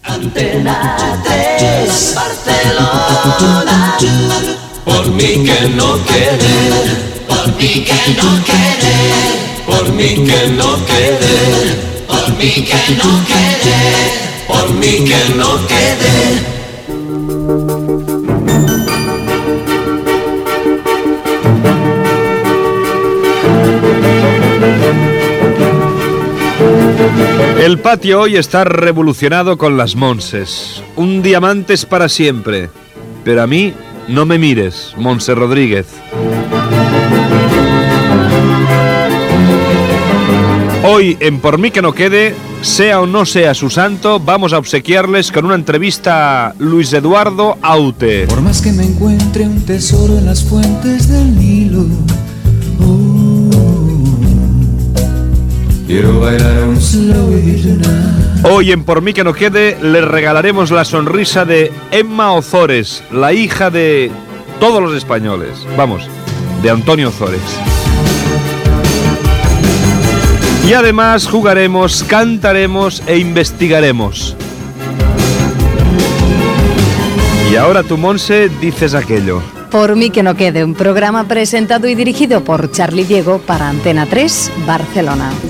Indicatiu de l'emissora, salutació a les Montses i sumari del programa.
Entreteniment
FM